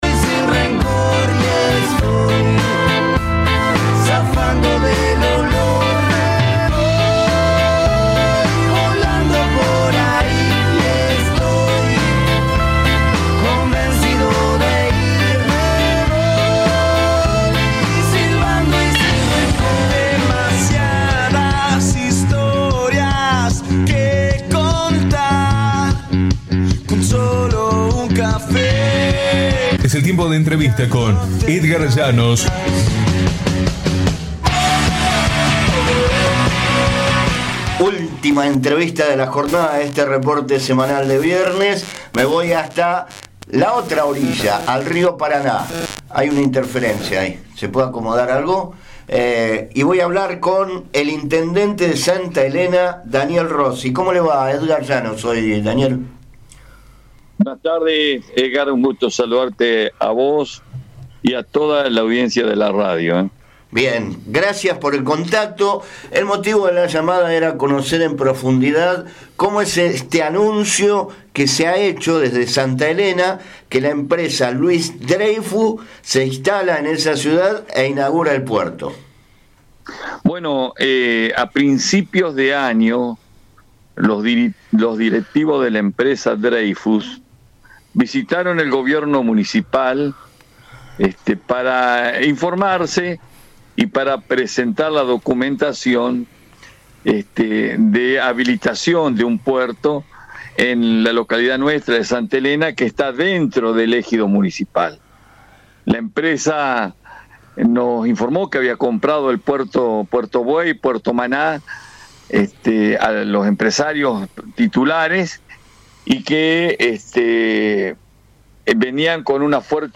Sobre las márgenes del Río Paraná, en la provincia de Entre Ríos, se encuentra la ciudad de Santa Elena, donde nos comunicamos con el jefe comunal Daniel Rossi para que cuente sobre la próxima inauguración de un puerto cerealero